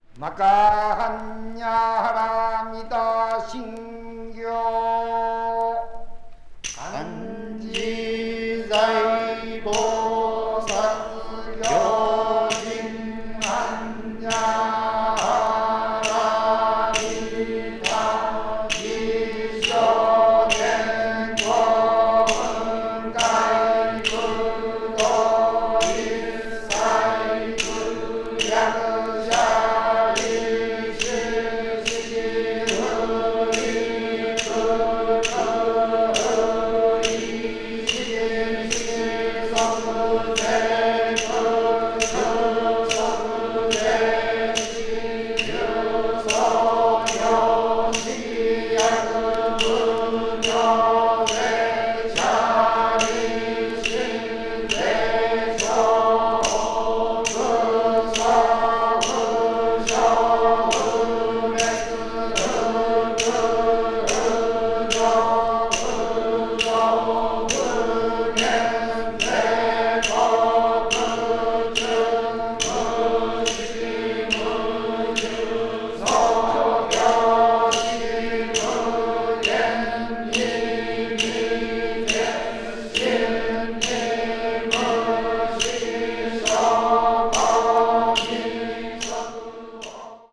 media : EX+/EX+(わずかにチリノイズが入る箇所あり)